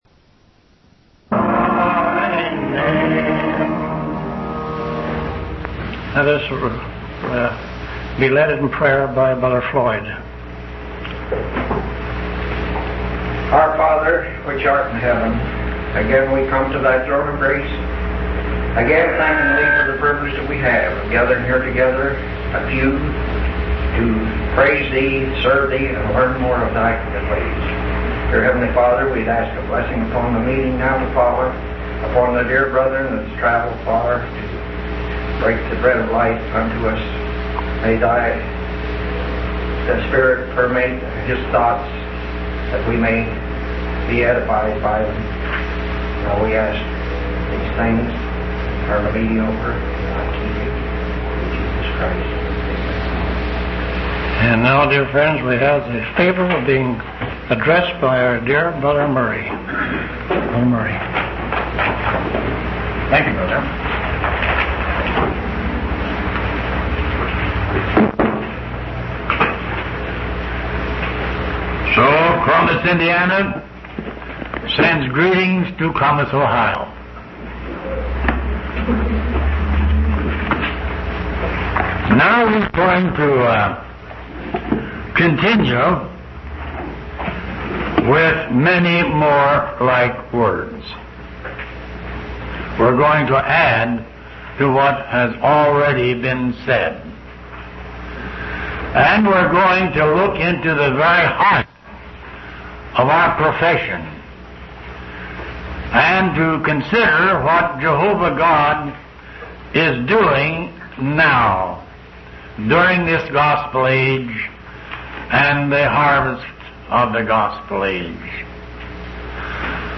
From Type: "Discourse"
Given in Columbus, OH